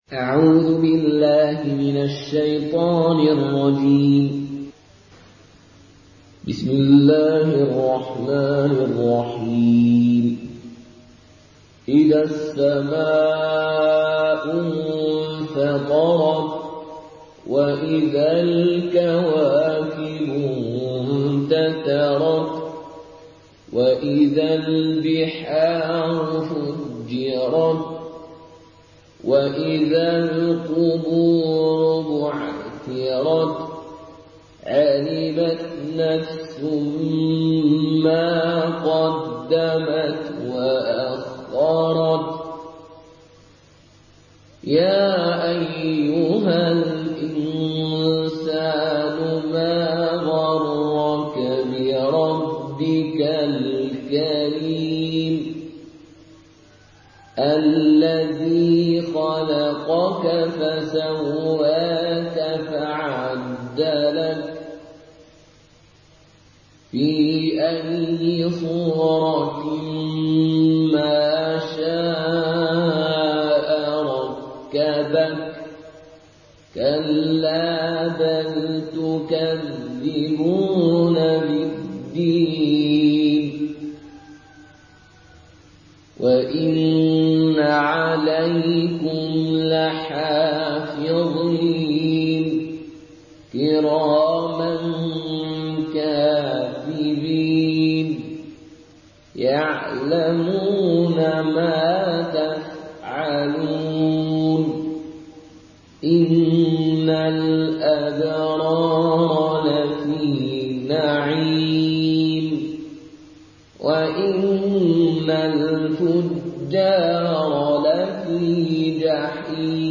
مرتل قالون عن نافع